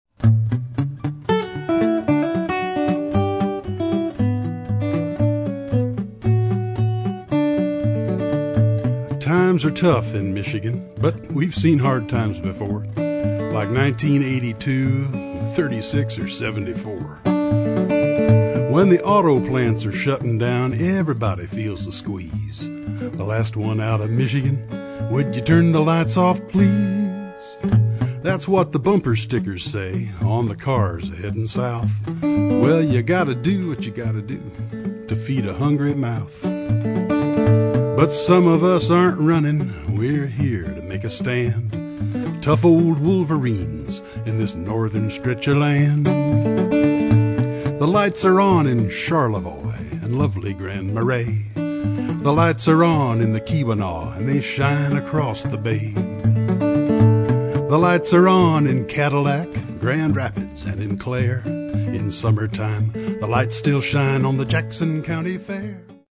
DIGITAL SINGLE